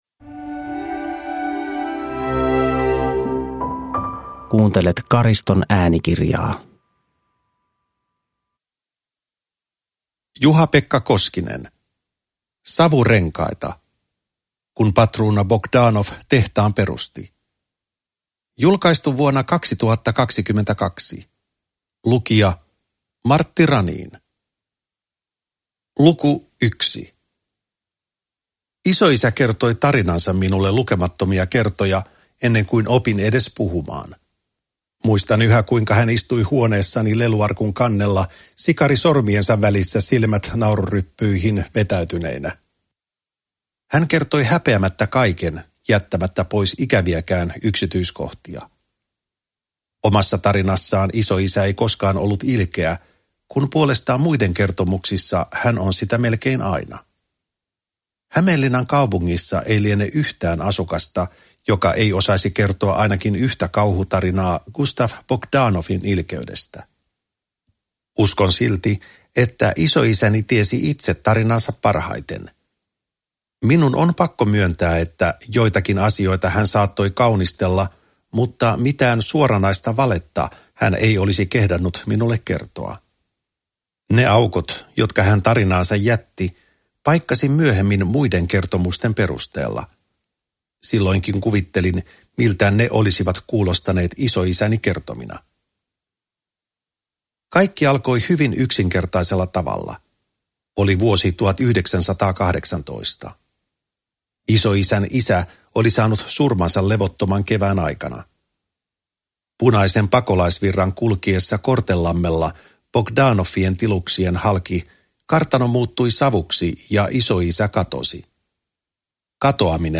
Savurenkaita – Ljudbok – Laddas ner